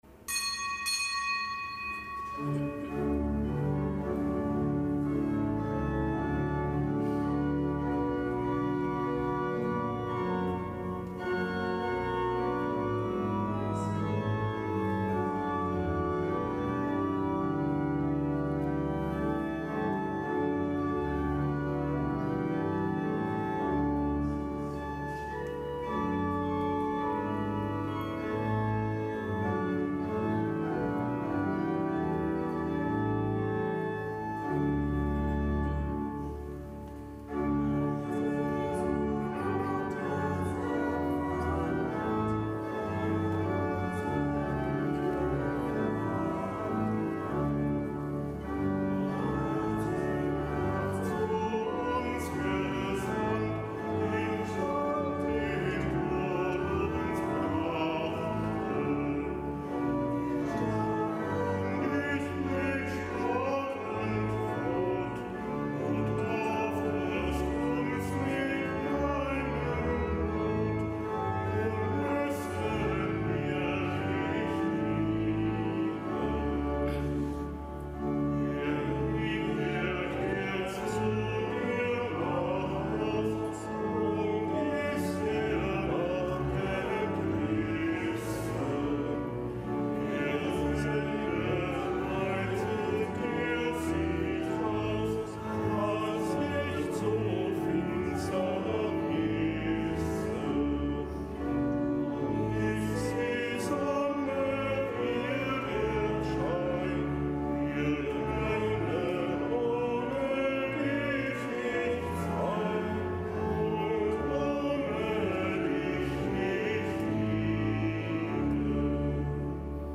Kapitelsmesse aus dem Kölner Dom am Freitag der vierten Fastenwoche (Herz-Jesu-Freitag). Nichtgebotener Gedenktag des Heiligen Isidor, Bischof von Sevilla, Kirchenlehrer.